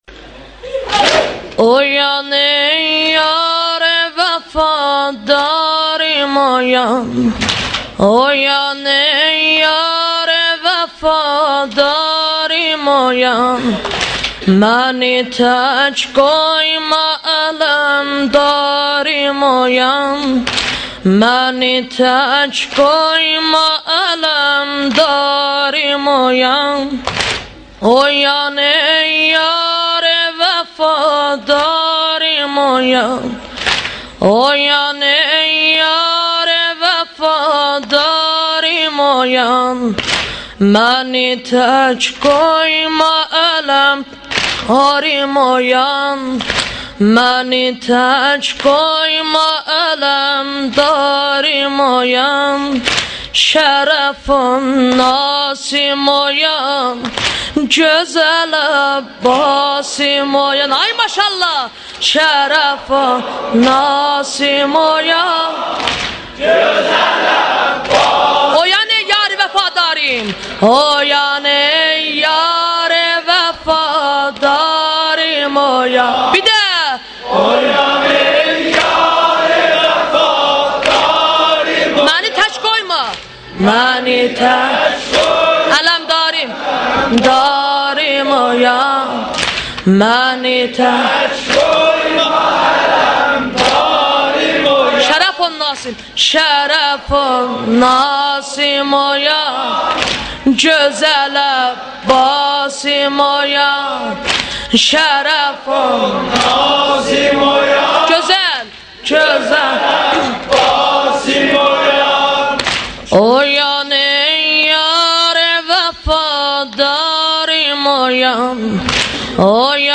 نوحه ترکی به صورت متن و صدا